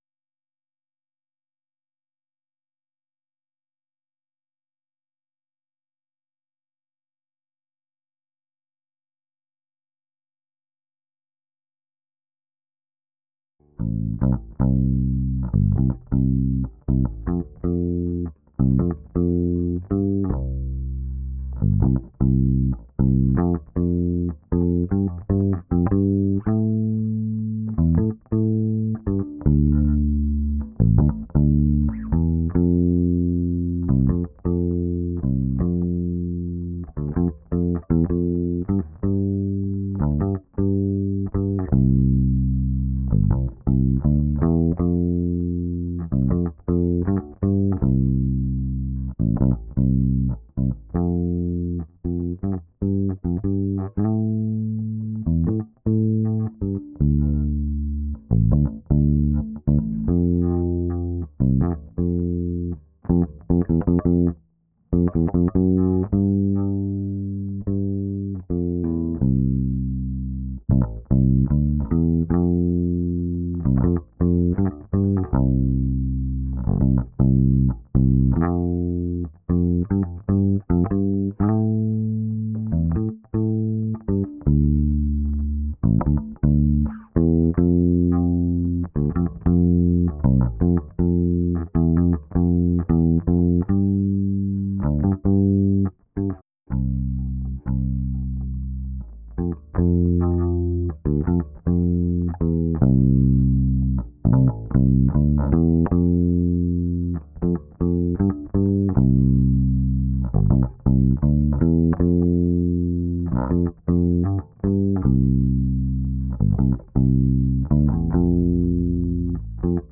bassguitar.wav